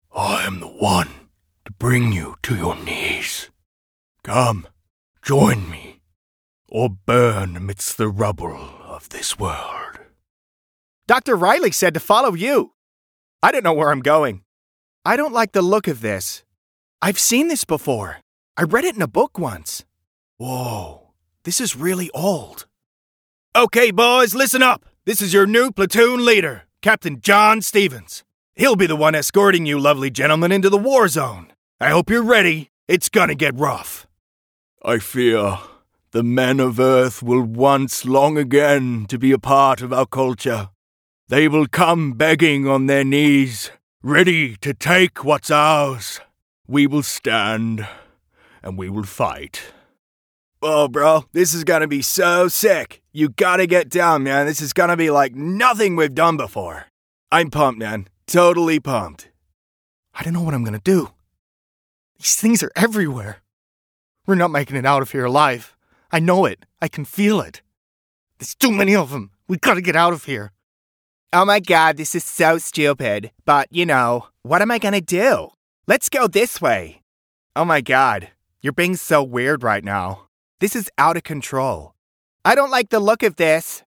Male
English (Australian)
Adult (30-50)
My voice although very versatile has been described as warm, engaging, Aussie slang, natural, professional, confident, strong, professional and friendly to name a few.
Character / Cartoon
1203Gaming_Scripts.mp3